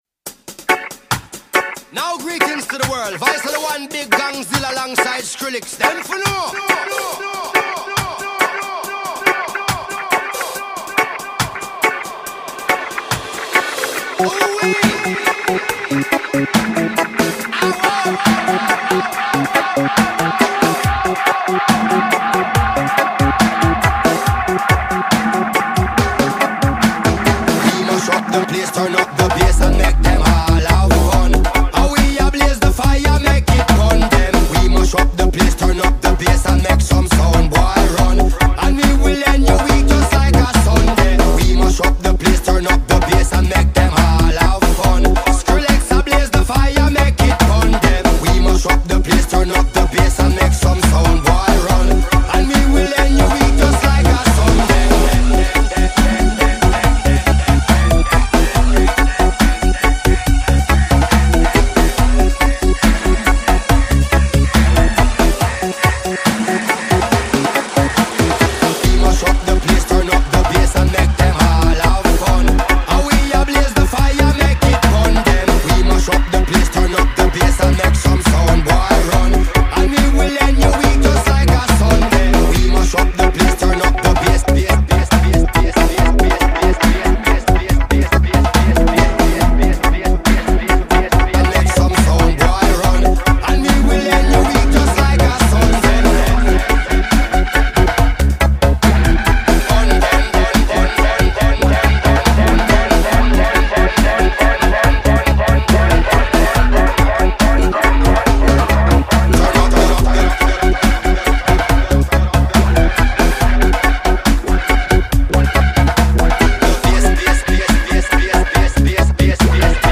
Remix Sevnica - Slovenia 2013